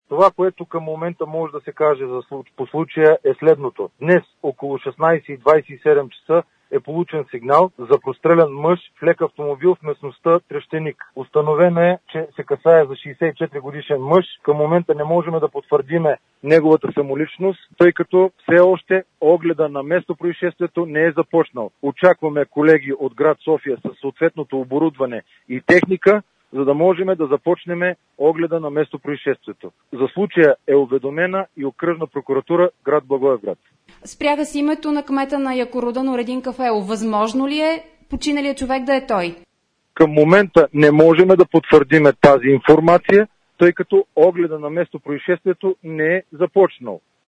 Звук: Георги Кандев, директор на ОД на МВР - Благоевград: